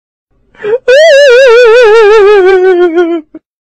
Royal Crying Sound Button - Free Download & Play